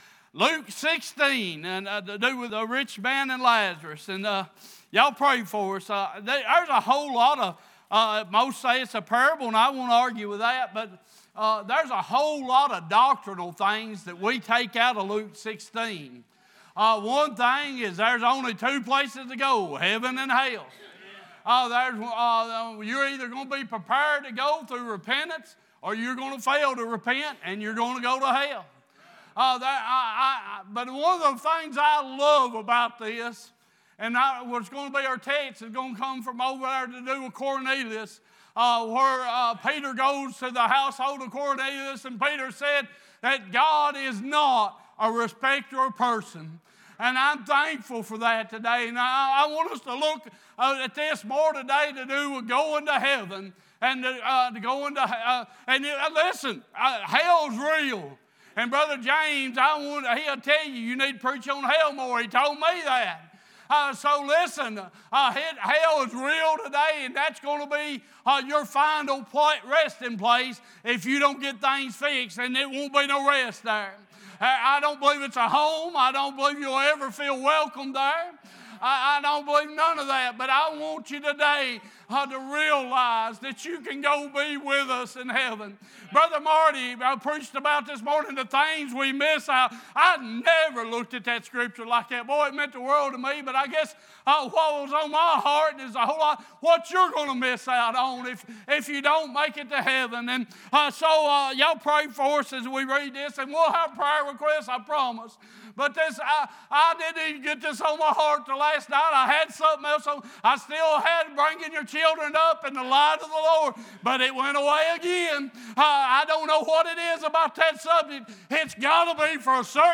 2 Corinthians 5:1 Service Type: Worship « Sleeping Like Jesus Unity “Oneness” »